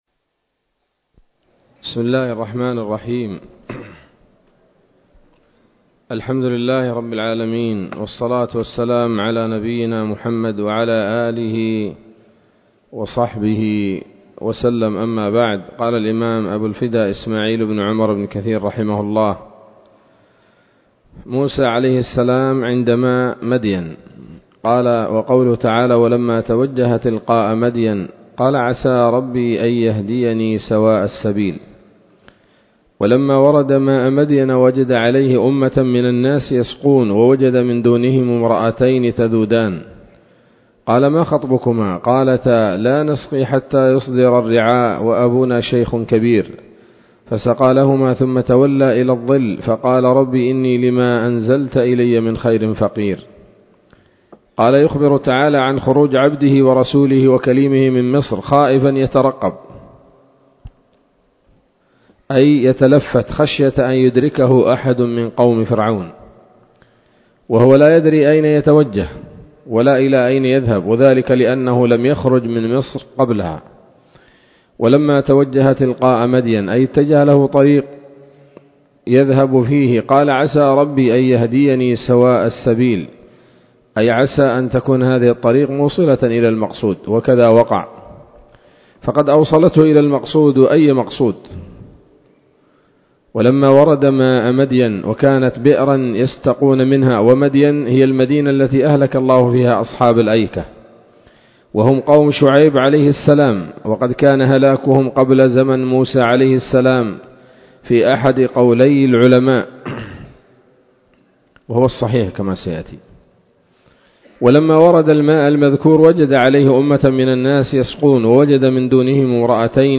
الدرس الثالث والثمانون من قصص الأنبياء لابن كثير رحمه الله تعالى